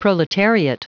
Prononciation du mot proletariat en anglais (fichier audio)
Prononciation du mot : proletariat